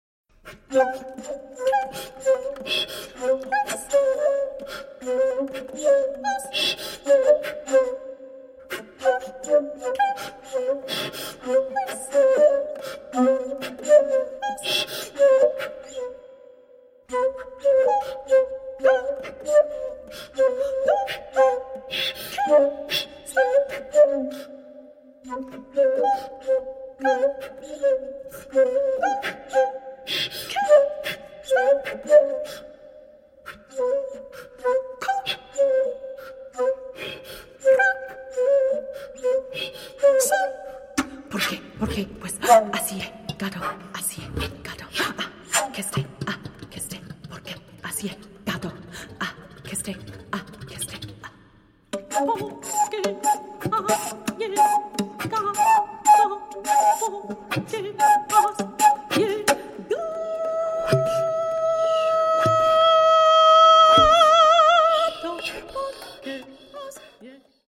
soprano and flute